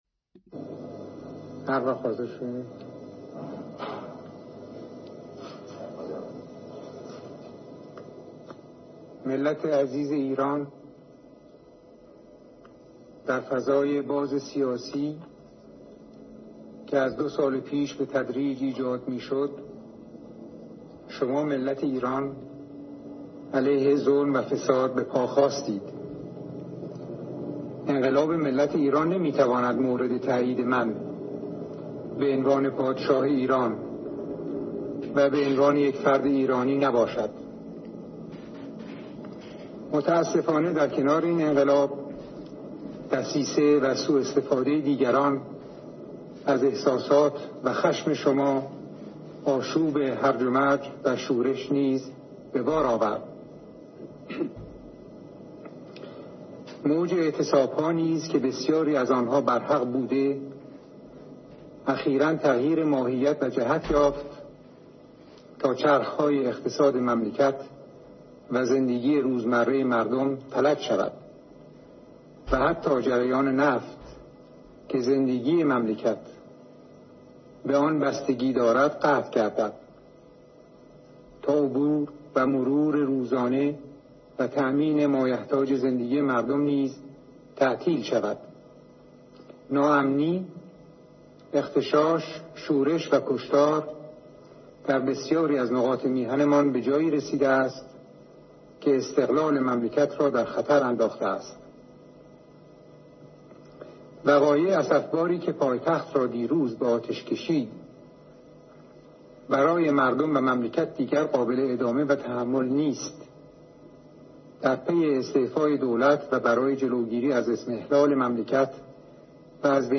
محمدرضا پهلوی: «من نیز پیام انقلاب شما را شنیدم» ۱۴ آبان ۱۳۵۷-پیام تلوزیونی، بعد از کشتار دانش‌آموزان در ۱۳ آبان ۱۳۵۷ و استعفا/برکناری نخست‌وزیر جعفر شریف امامی، و منصوب کردن ارتشبد غلامرضا ازهاری، به عنوان نخست‌وزیر «یک دولت موقت» :